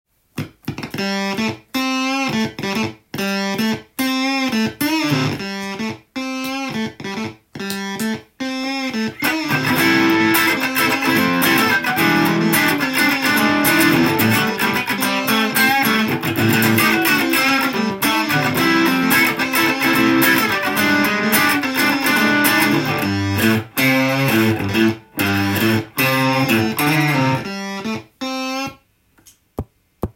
ルーパーとカーディアンのオーバードライブも繋げて実験しました
ファズの音をルーパーに録音させて再生させて
オーバードライブで一人セッションです。